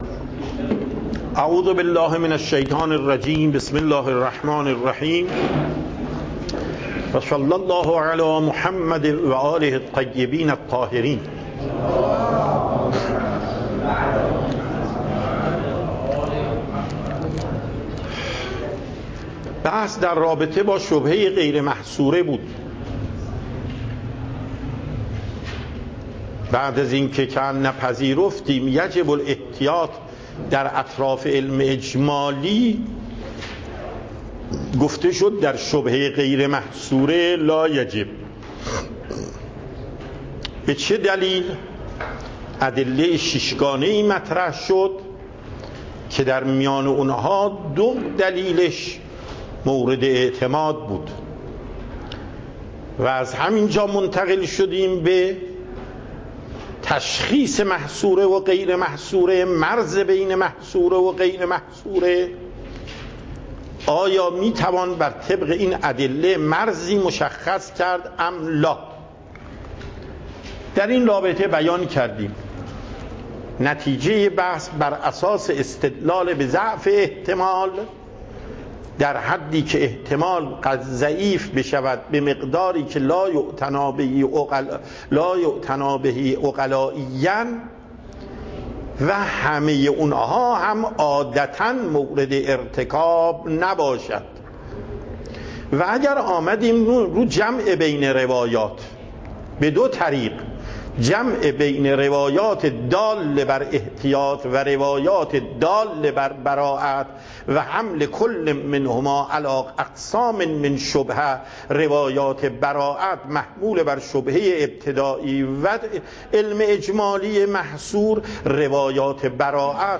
درس اصول آیت الله محقق داماد